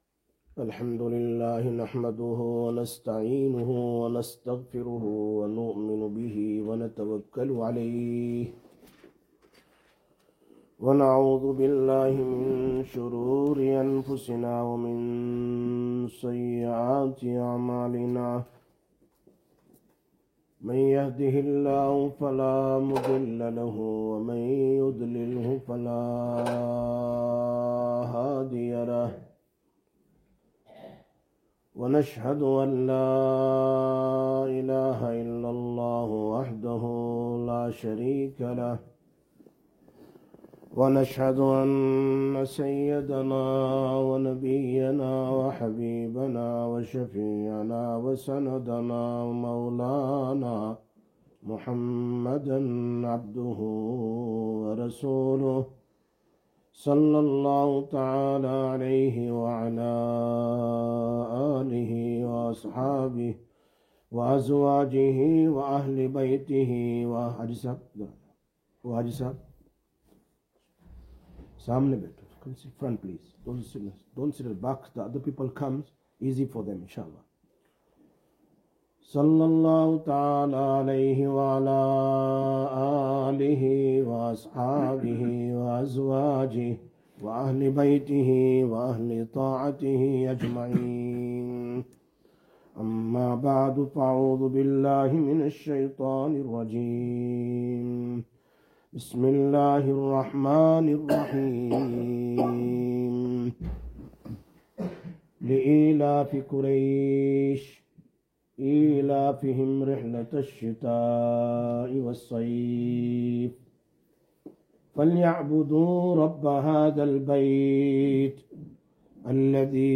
09/12/2022 Jumma Bayan, Masjid Quba